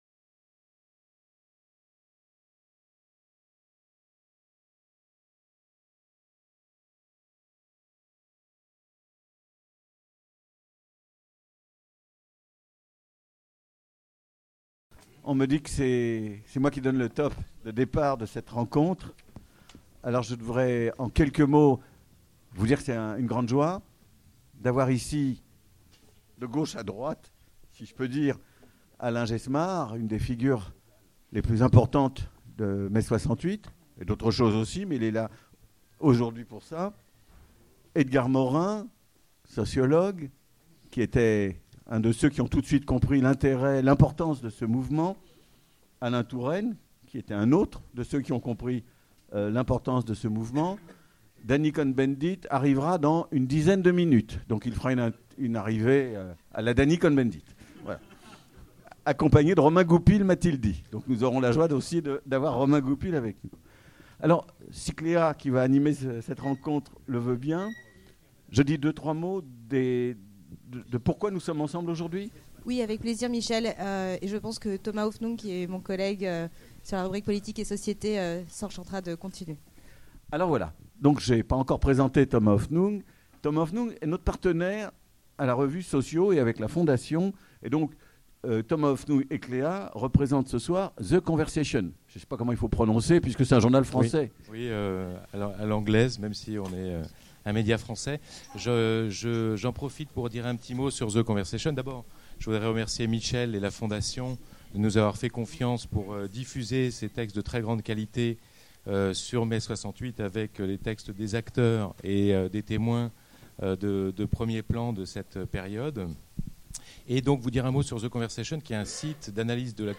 Revue Socio n°10 - Rencontre avec Daniel Cohn-Bendit, Alain Geismar, Edgar Morin, Alain Touraine et Michel Wieviorka | Canal U